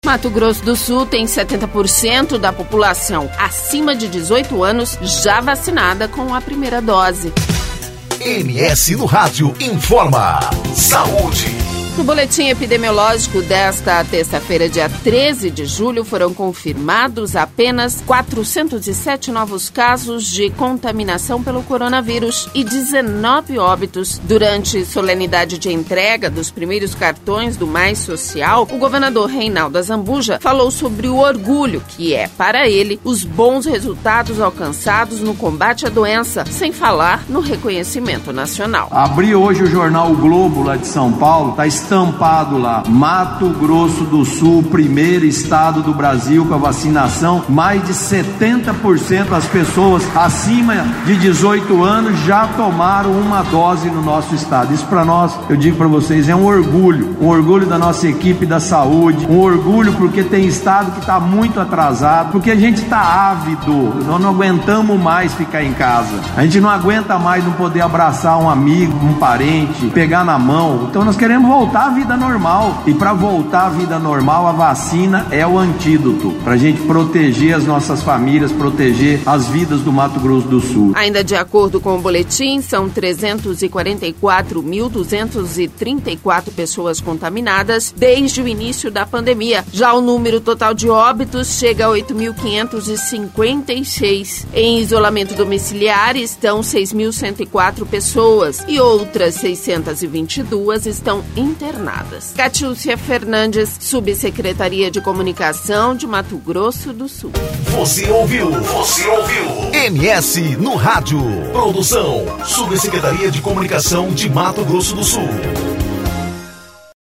No boletim epidemiológico desta terça-feira, dia 13 de julho, foram confirmados apenas 407 novos casos de contaminação pelo coronavírus e 19 óbitos. Durante solenidade de entrega dos primeiros cartões do Mais Social, o governador Reinaldo Azambuja falou sobre o orgulho que é para ele, os bons resultados alcançados no combate a doença, sem falar no reconhecimento nacional.